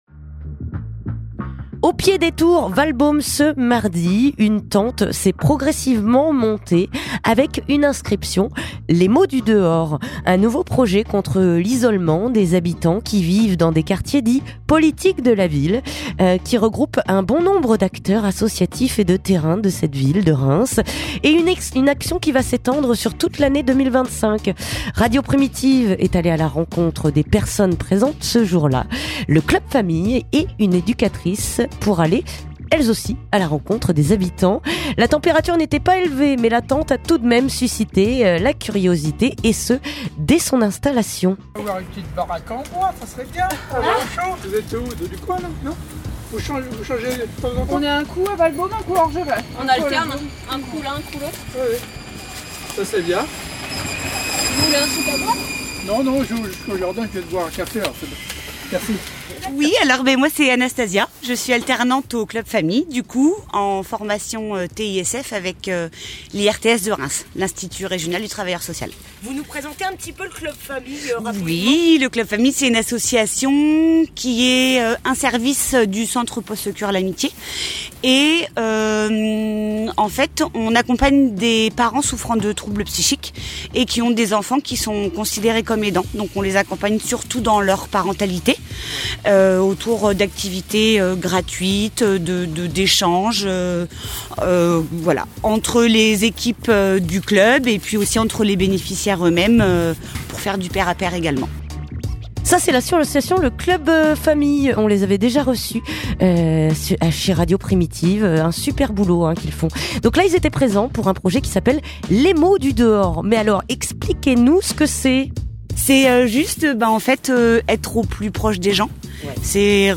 Reportage aux tours Walbaum (6:43)